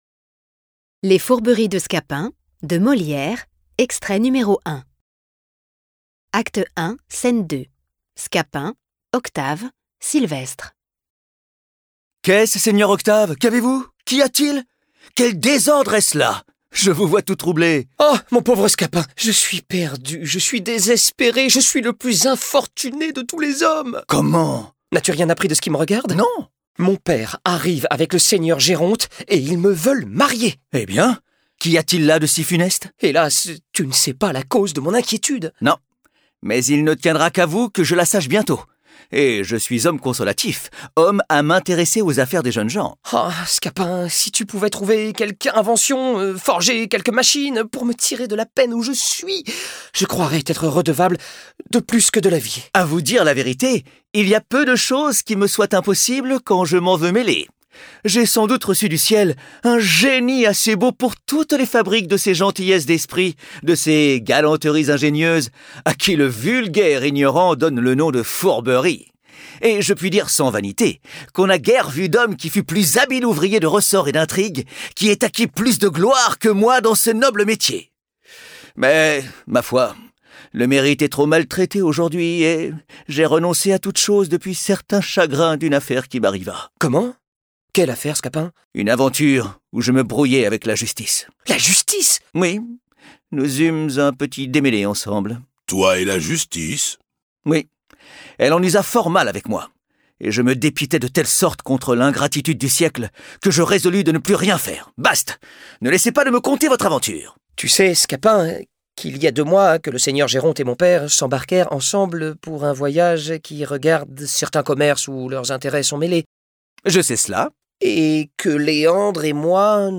Les Fourberies de Scapin, de Molière • Acte I, scène 2 • lignes 1 à 70 (1er extrait lu)